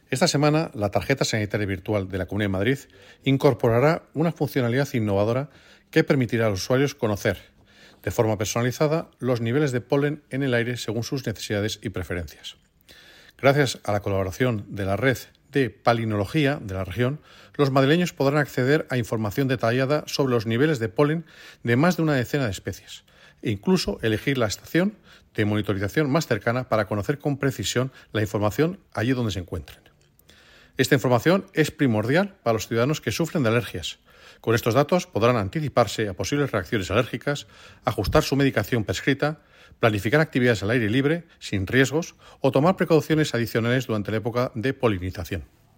Declaraciones del consejero en la página web de la C. de Madrid con la nota de prensa]